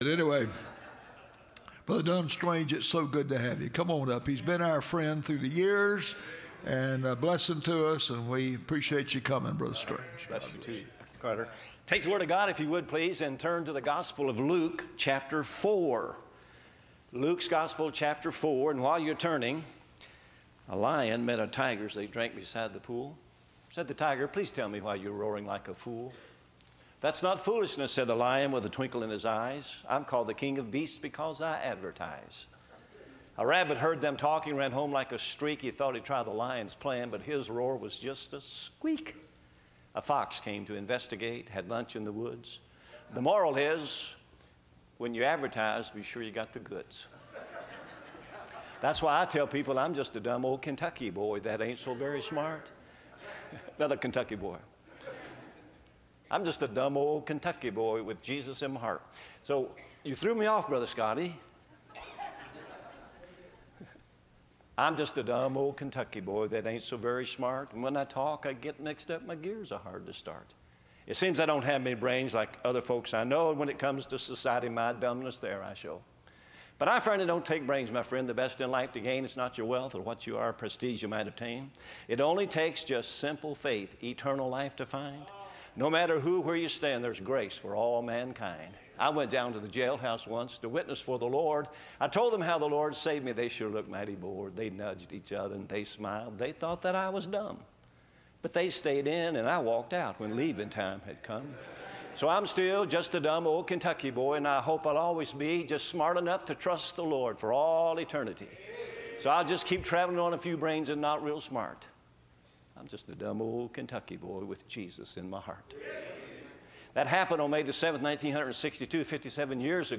Service Type: Bible Conference